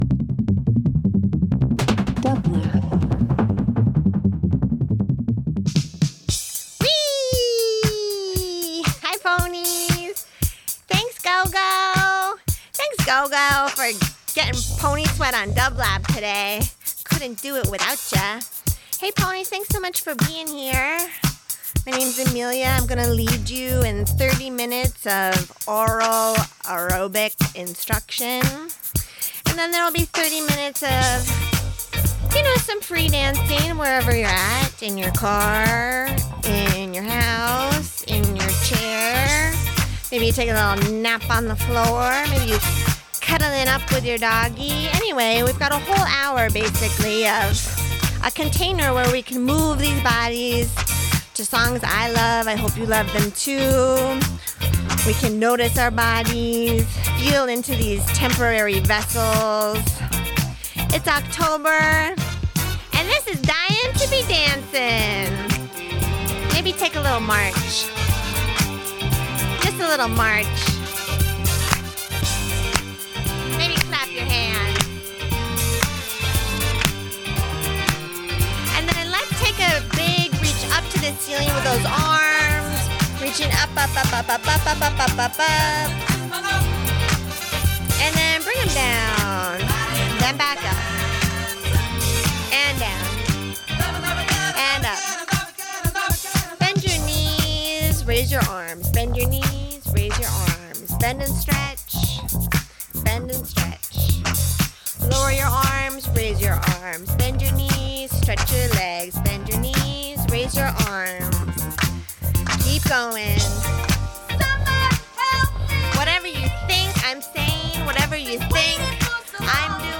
In this 30 min of aural aerobics set to some favorite tunes
House Pop Rock